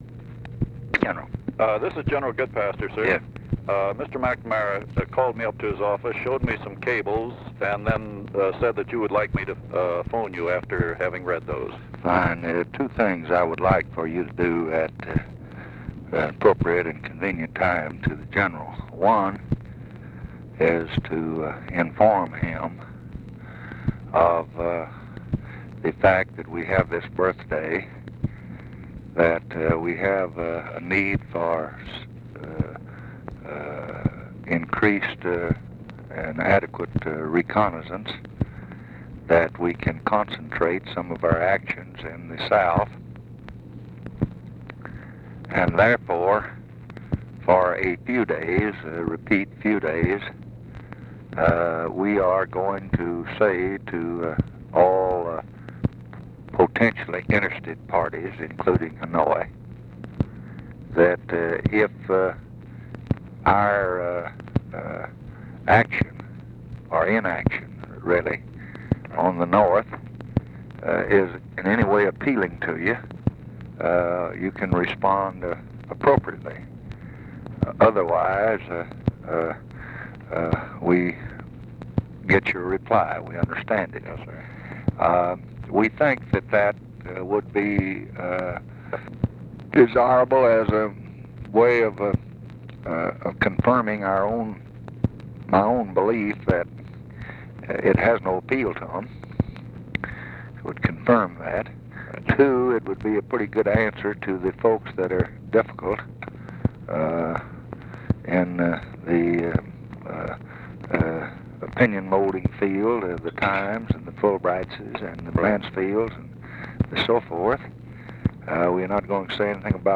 Conversation with ANDREW GOODPASTER, May 12, 1965
Secret White House Tapes